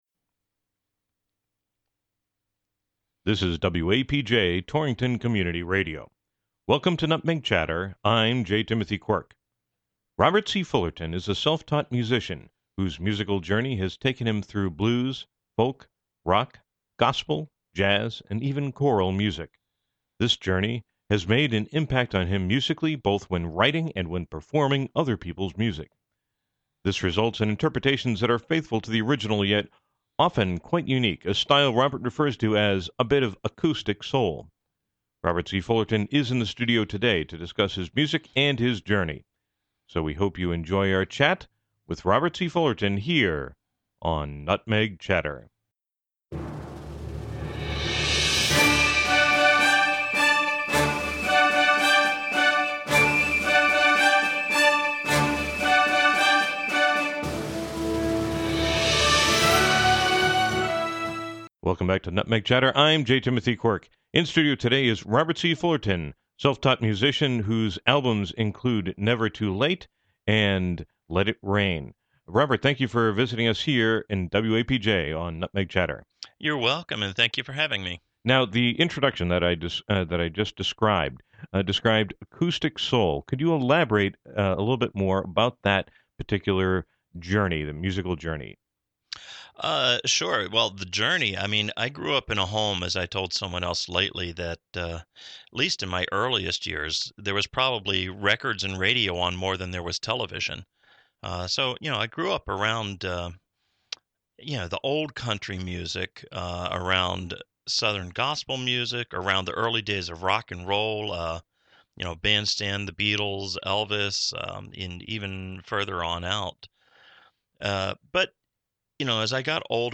Clips from his music are used with permission.
Radio Show